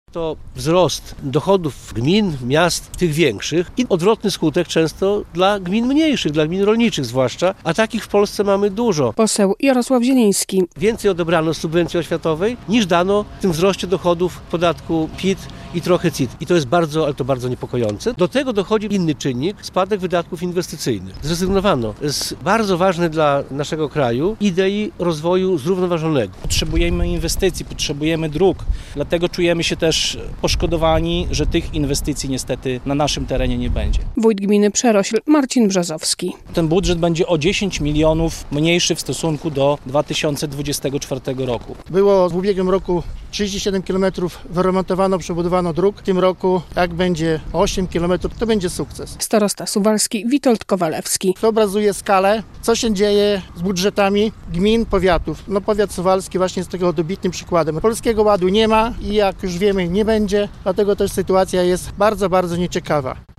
Jarosław Zieliński podczas konferencji prasowej (17.06) przed siedzibą starostwa powiatowego w Suwałkach zwrócił uwagę na niepokojące zmiany, jakie obecny rząd wprowadza w zasadach finansowania jednostek samorządu terytorialnego i likwidację subwencji oświatowej.